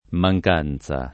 [ ma j k # n Z a ]